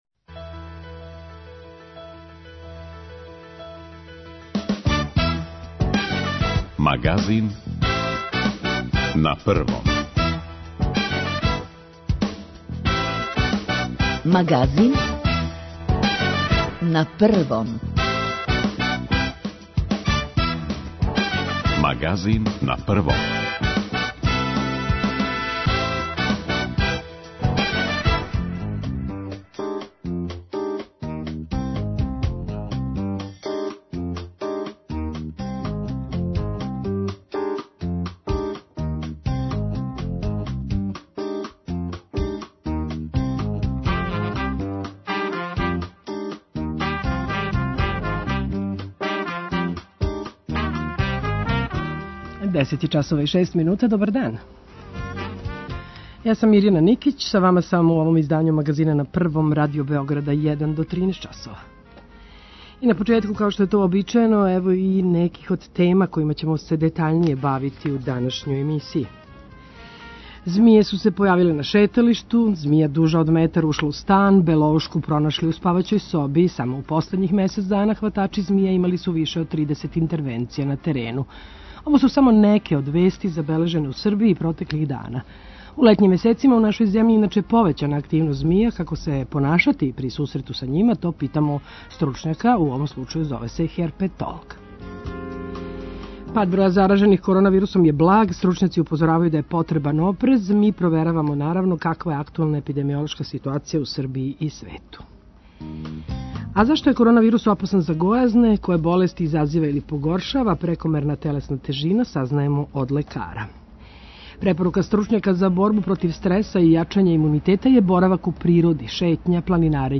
Ово су само неке од вести забележене у Србији протеклих дана. У летњим месецима у нашој земљи иначе је повећана активност змија, како се понашати при сусрету са њма питамо херпетолога.
Које болести изазива или погоршава прекомерна телесна тежина, сазнајемо од лекара.